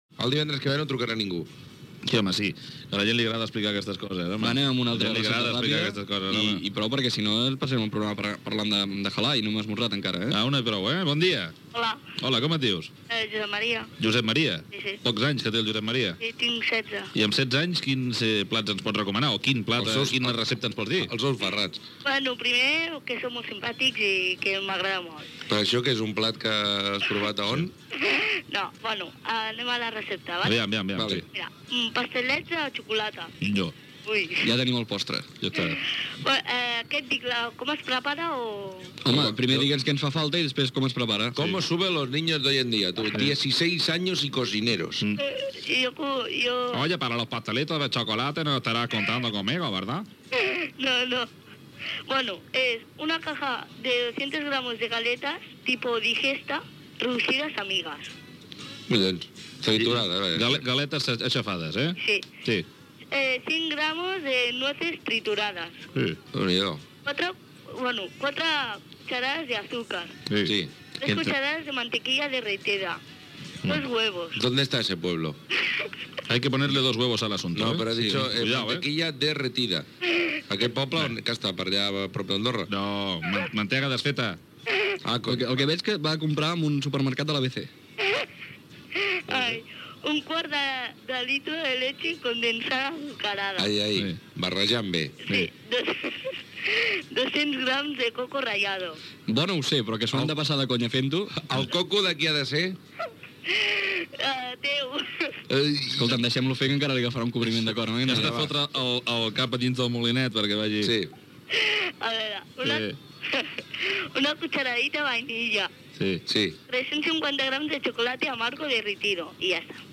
Trucada d'un jove oïdor per explicar la recepte dels pastissets de xocolata
Entreteniment
FM